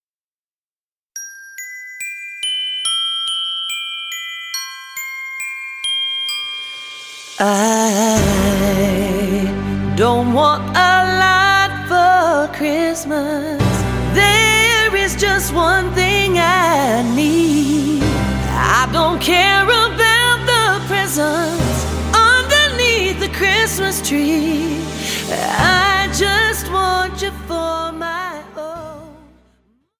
Chant et Piano